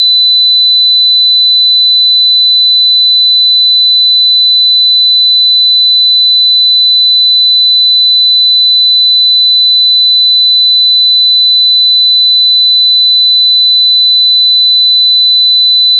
いろいろな周波数の音を聞いてみよう